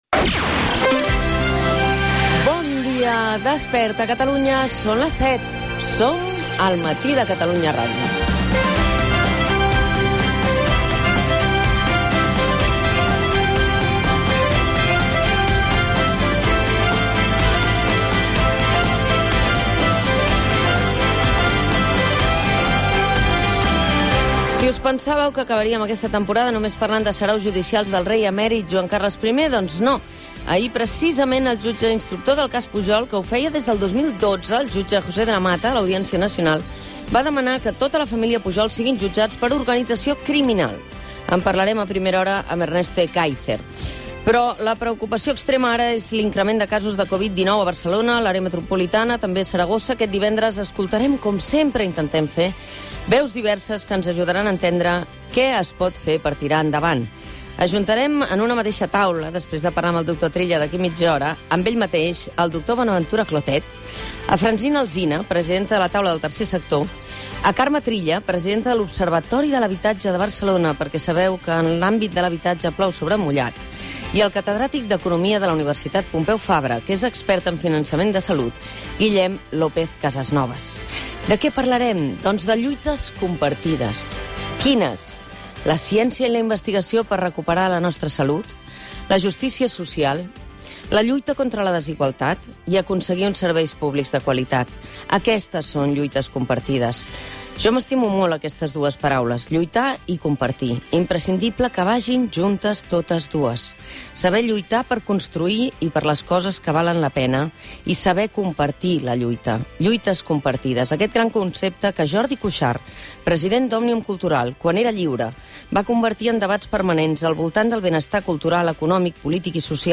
Info-entreteniment
Última edició de "El matí de Catalunya Ràdio" presentada per Mònica Terribas.